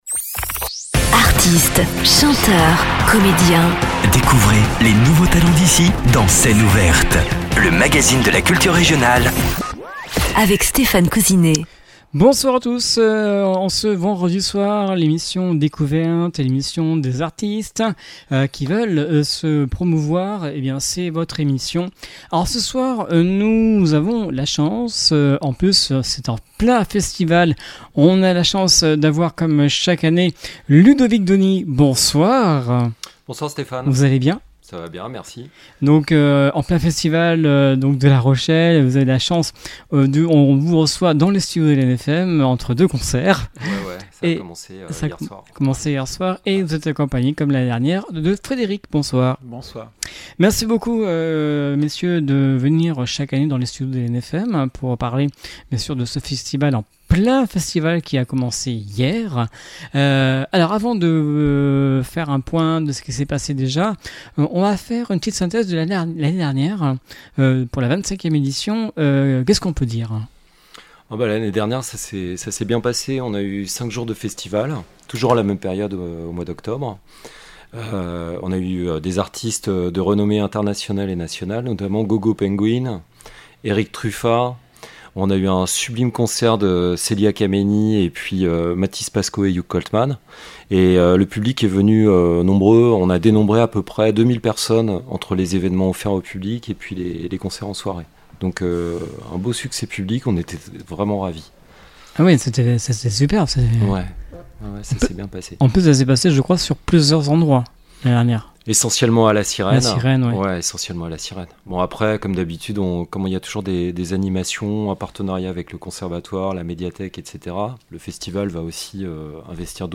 Scène ouverte avec les organisateurs de La Rochelle Jazz Festival